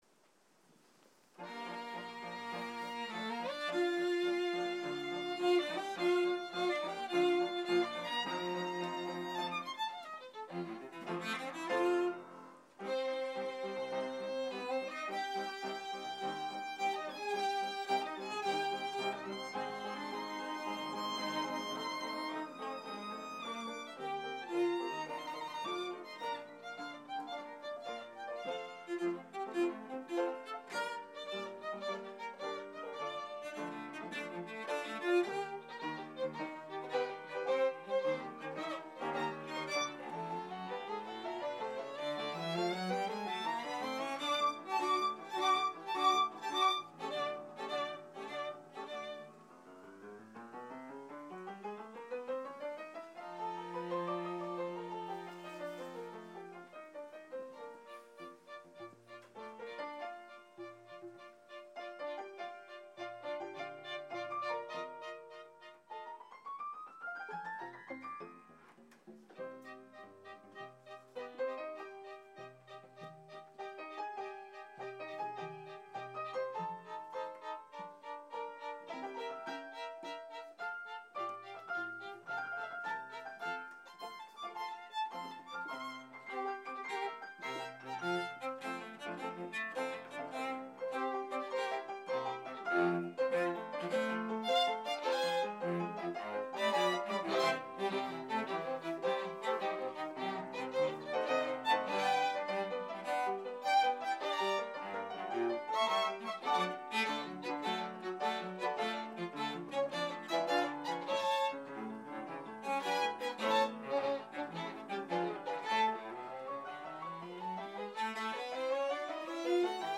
Allegro moderato